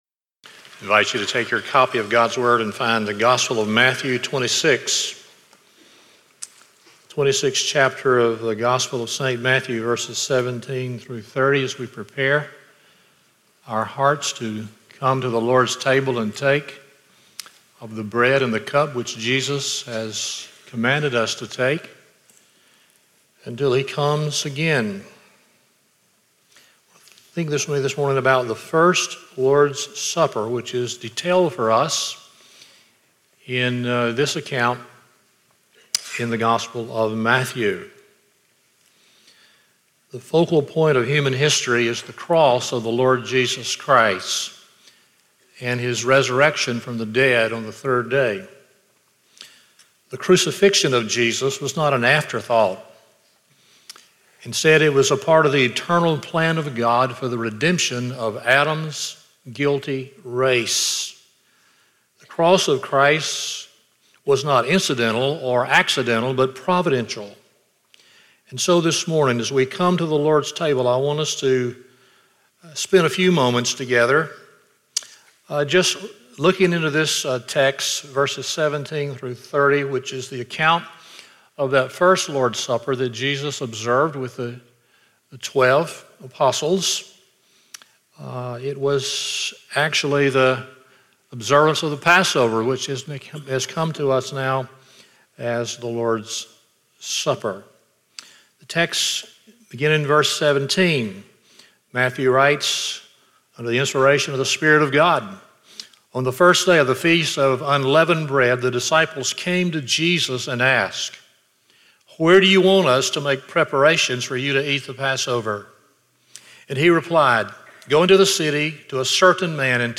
Stand Alone Sermons Passage: Matthew 26:17-30 Service Type: Sunday Morning 1.